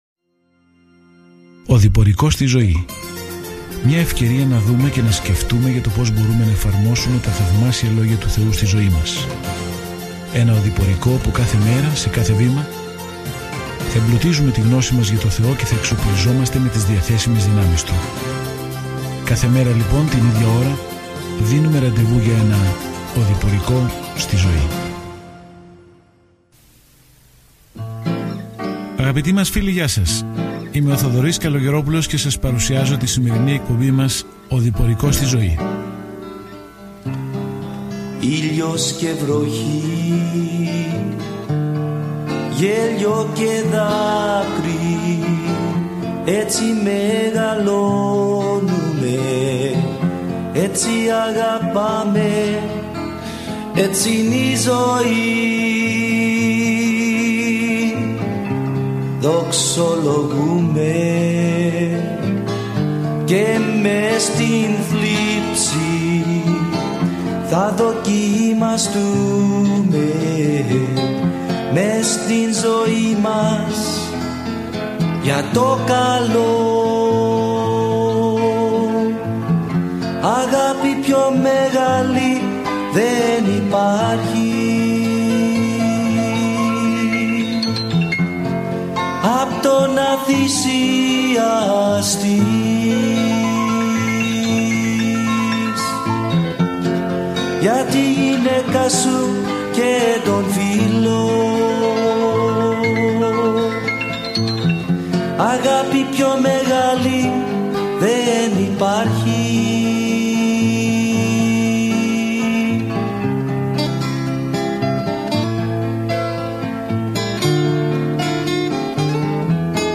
Κείμενο ΙΗΣΟΥΣ ΤΟΥ ΝΑΥΗ 4 ΙΗΣΟΥΣ ΤΟΥ ΝΑΥΗ 5 ΙΗΣΟΥΣ ΤΟΥ ΝΑΥΗ 6 Ημέρα 2 Έναρξη αυτού του σχεδίου Ημέρα 4 Σχετικά με αυτό το σχέδιο Ας ονομάσουμε το βιβλίο του Ιησού του Ναυή, «Έξοδος: Μέρος δεύτερο», καθώς μια νέα γενιά του λαού του Θεού παίρνει τη γη που τους υποσχέθηκε. Καθημερινά ταξιδεύετε στον Τζόσουα καθώς ακούτε την ηχητική μελέτη και διαβάζετε επιλεγμένους στίχους από τον λόγο του Θεού.